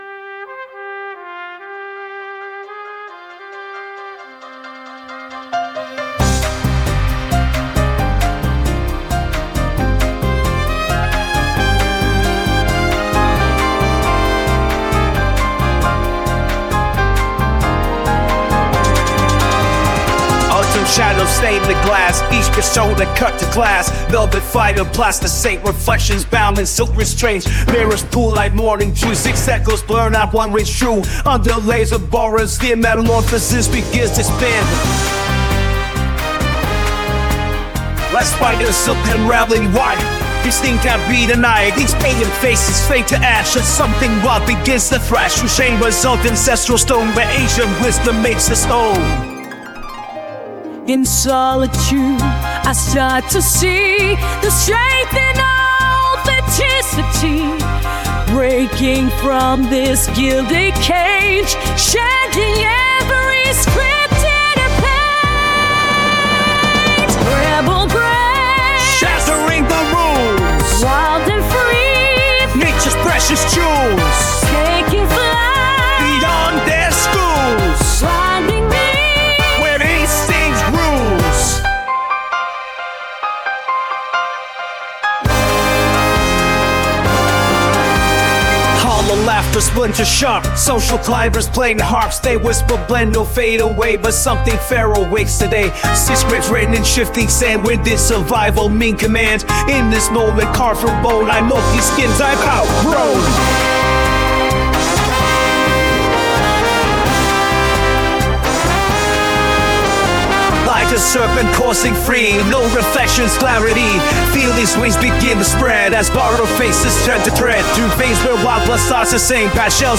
uses mixolydian mode for rap